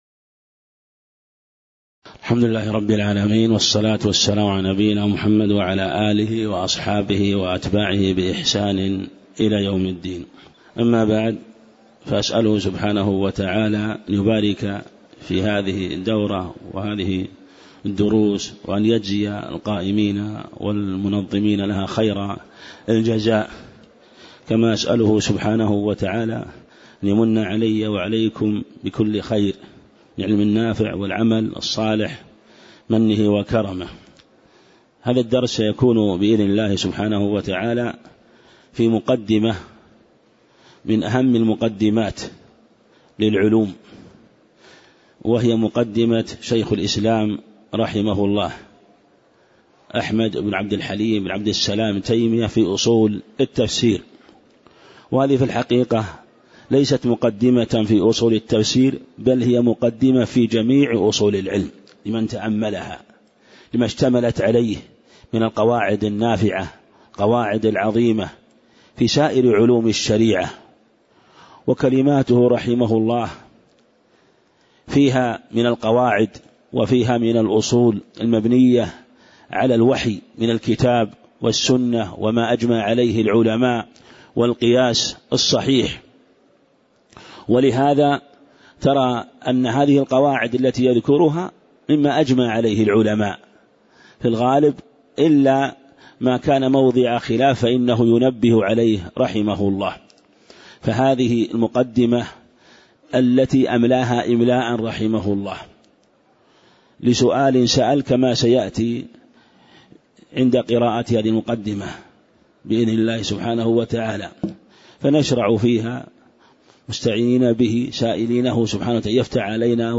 تاريخ النشر ١٤ شوال ١٤٣٨ هـ المكان: المسجد النبوي الشيخ